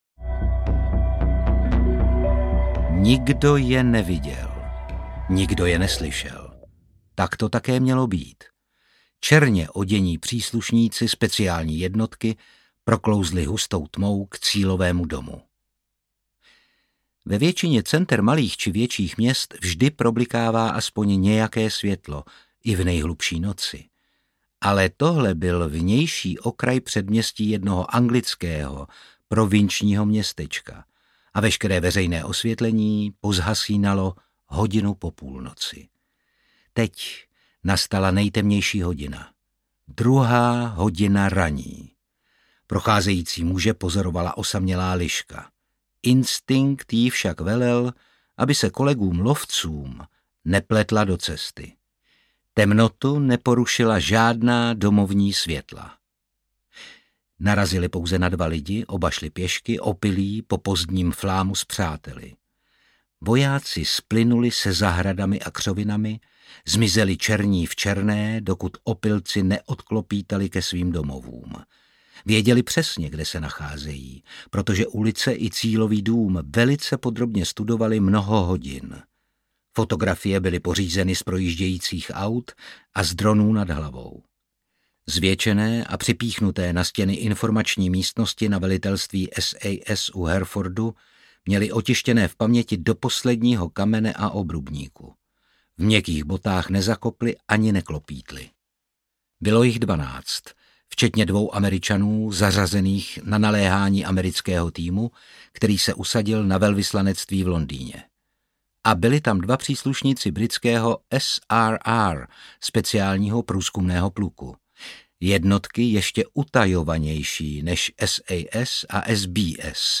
Liška audiokniha
Ukázka z knihy
• InterpretOtakar Brousek ml.